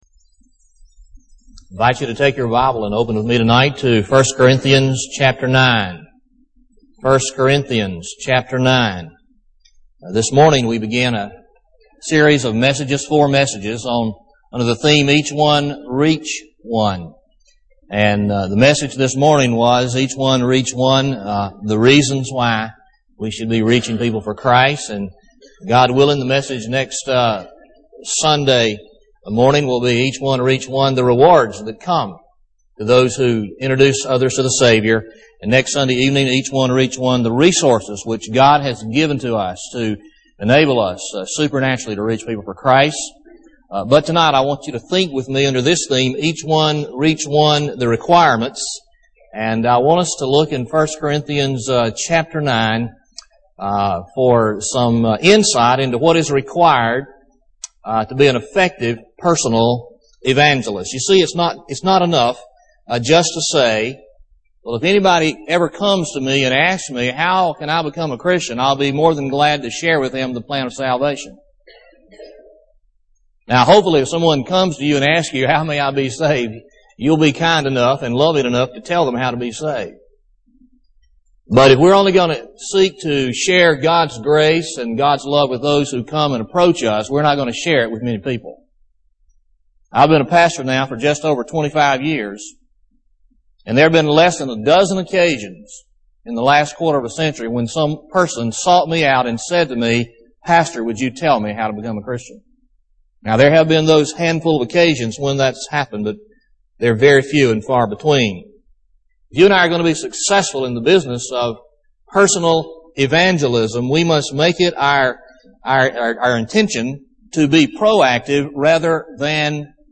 Lakeview Baptist Church - Auburn, Alabama
Sermon